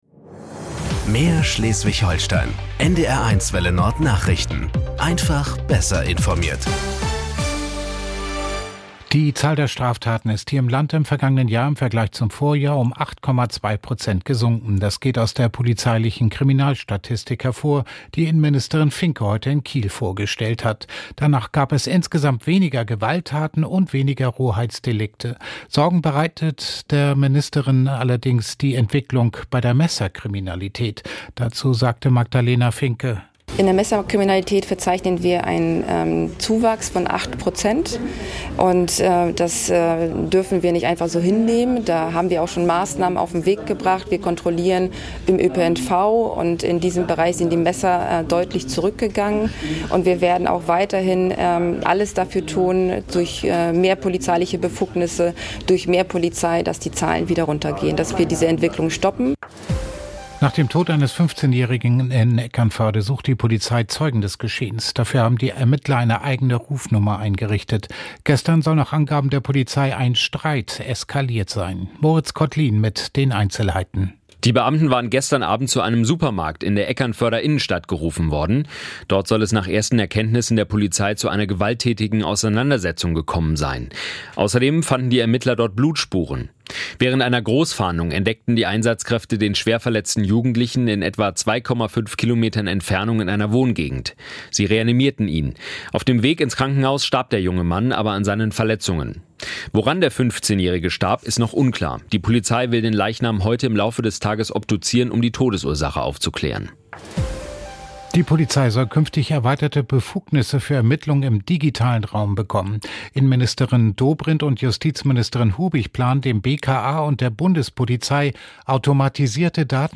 Nachrichten 13:00 Uhr - 12.03.2026 ~ NDR 1 Welle Nord – Nachrichten für Schleswig-Holstein Podcast